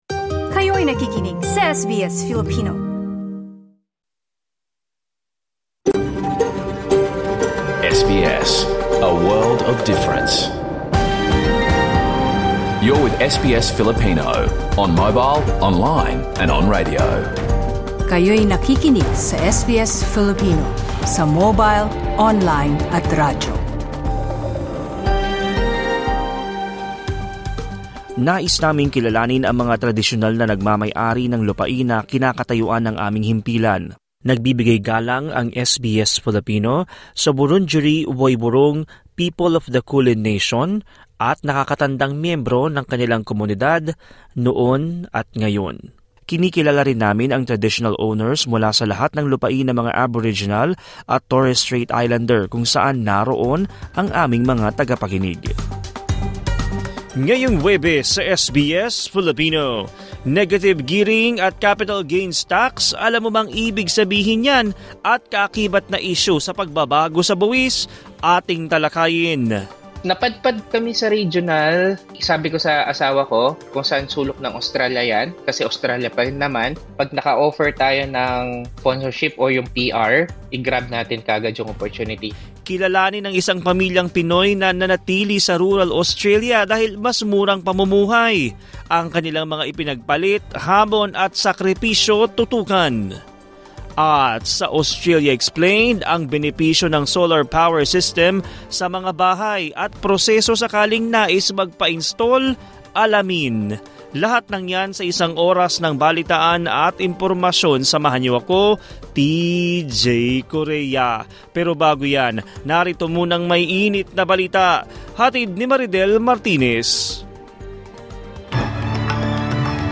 SBS News in Filipino, Thursday 26 September 2024